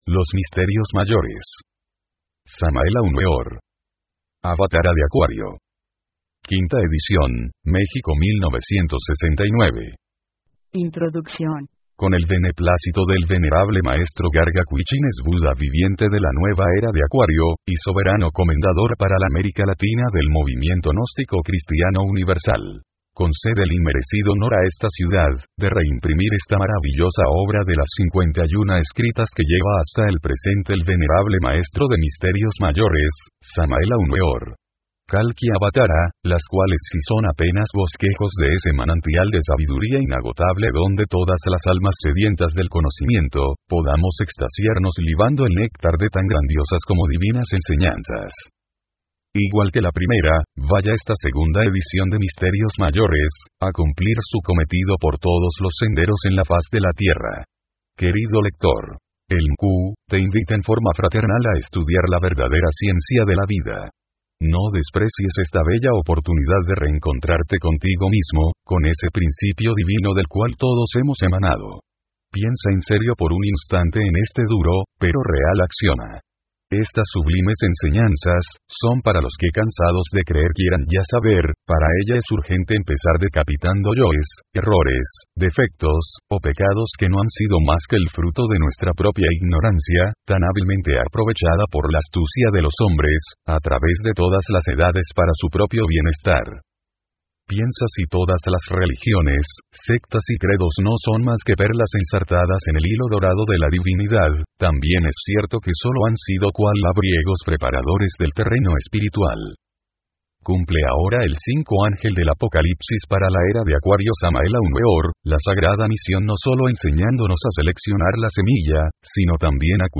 Audiolibros del maestro Samael Aun Weor